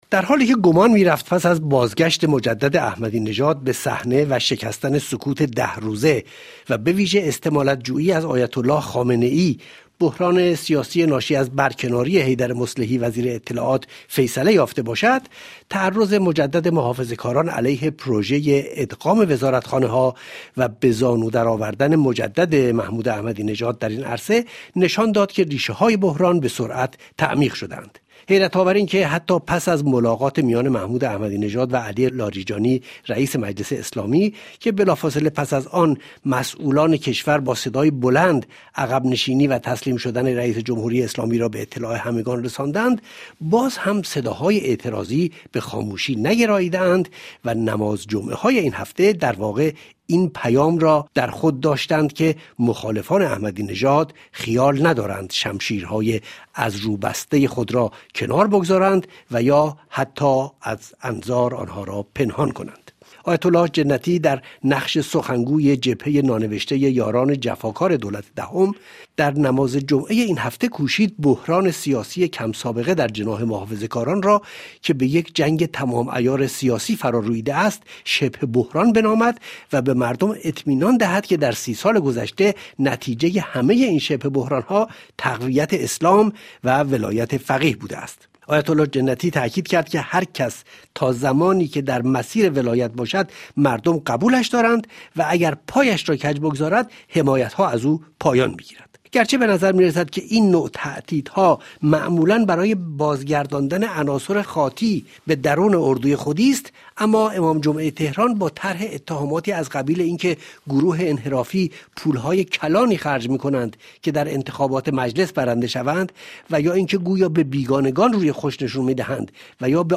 این میزگرد رادیویی را بشنوید